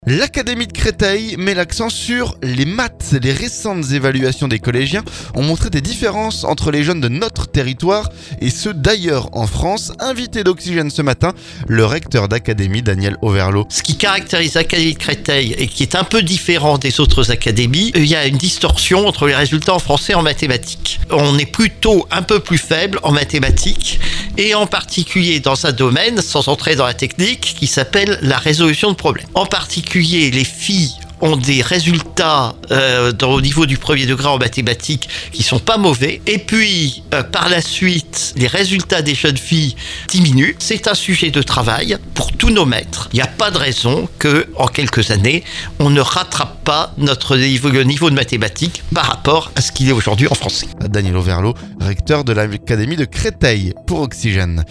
Les récentes évaluations des collégiens ont montré des différences entre les jeunes de notre territoire et ceux d'ailleurs en France. Invité d'Oxygène ce vendredi, le recteur d'Académie Daniel Auverlot.